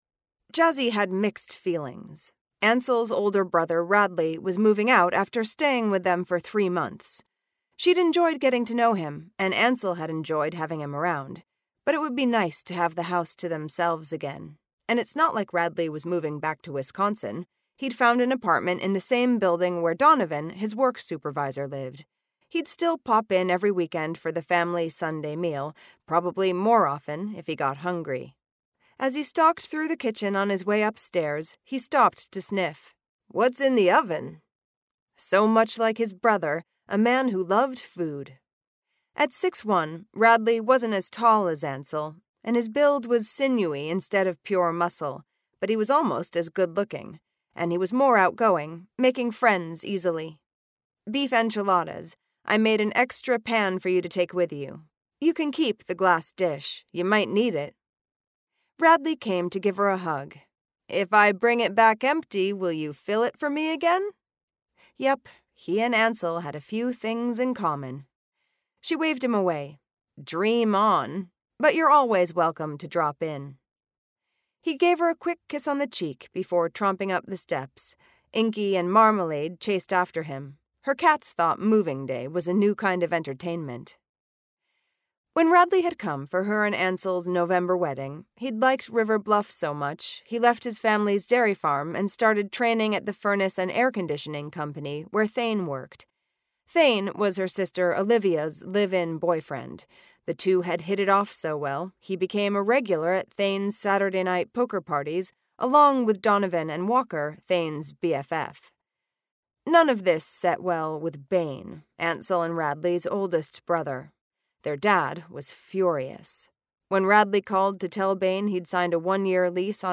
The Body in the Apartment - A Jazzi Zanders Mystery, Book Four - Vibrance Press Audiobooks - Vibrance Press Audiobooks